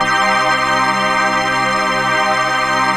DM PAD2-78.wav